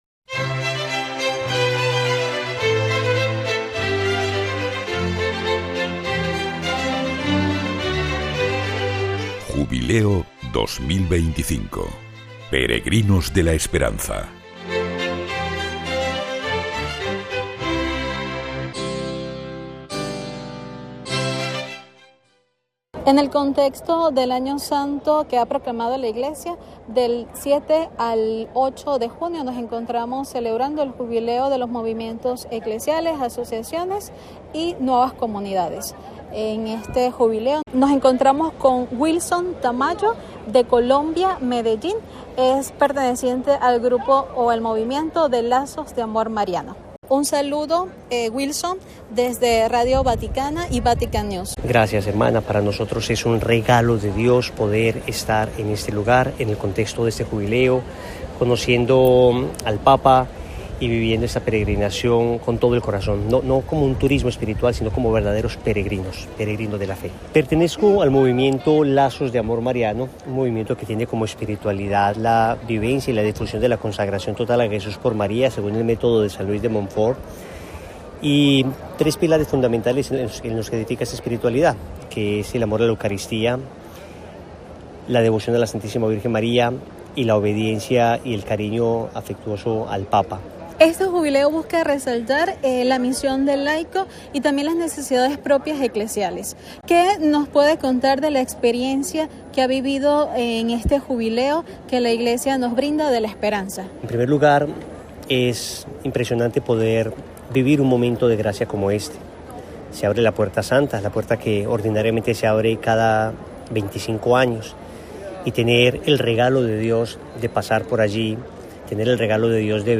AUDIO. Testimonio de laicos durante el Jubileo de movimientos y asociaciones